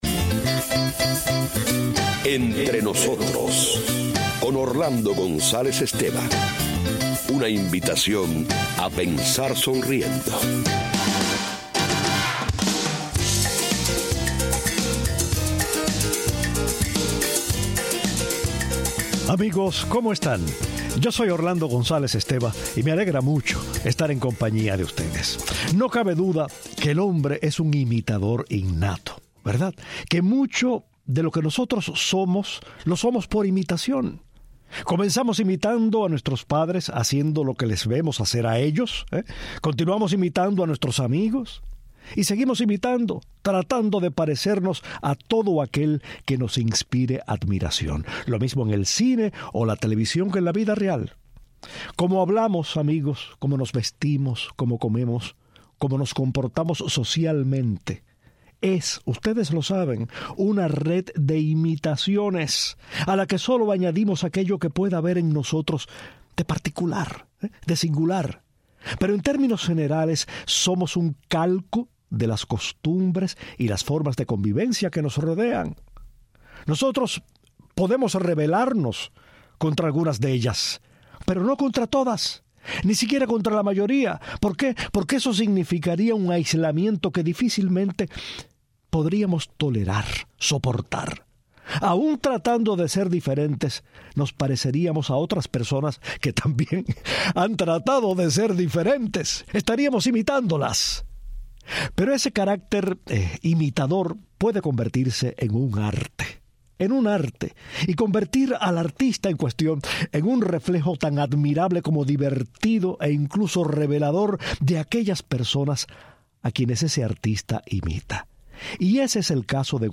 El gran imitador venezolano, estrella de la televisión y la radio, habla de su carrera y de los artistas, políticos y personalidades que ha imitado, al tiempo que lo escuchamos desdoblarse en José Feliciano, Nicolás Maduro, Nat King Cole, José Luis Rodríguez "El Puma", Carlos Gardel y otros.